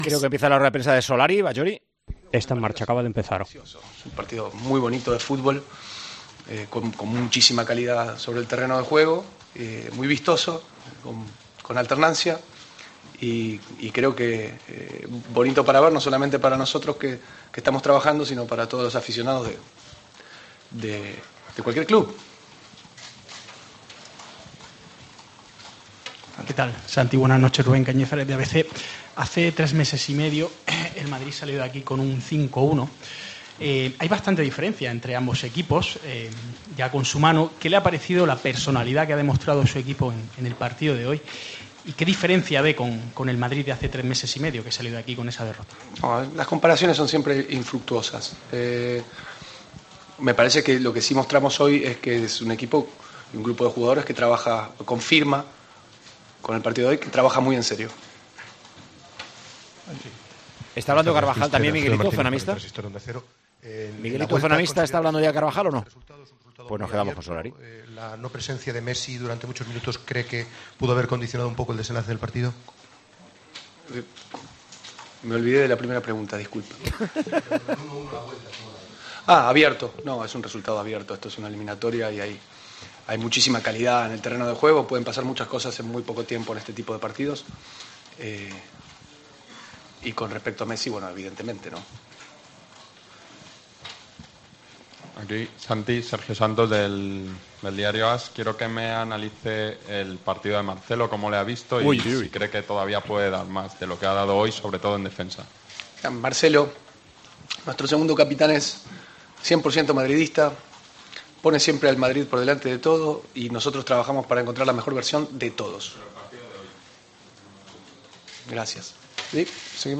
Solari, en rueda de prensa: "Es un partido vistoso, con un resultado abierto. Vinicius no me sorprende porque le conozco. Lucas es solidario y valiente".